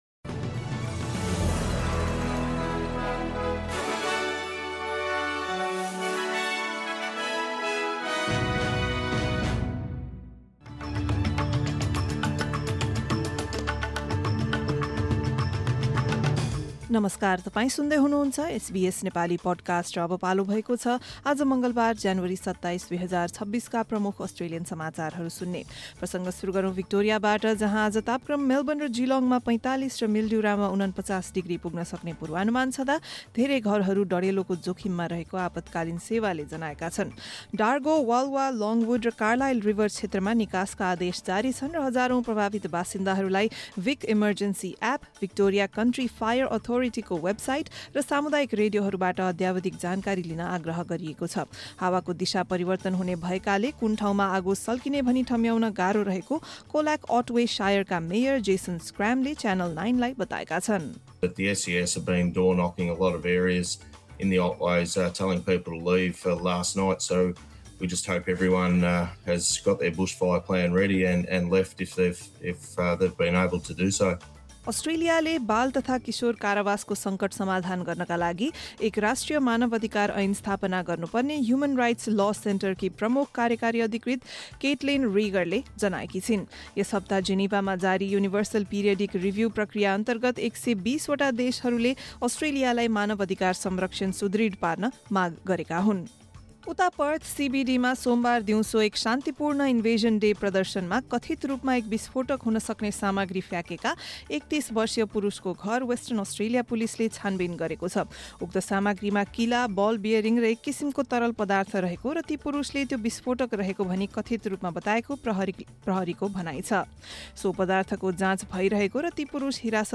SBS Nepali Australian News Headlines: Tuesday, 27 January 2026